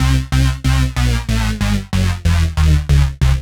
FR_Roughas_140-C.wav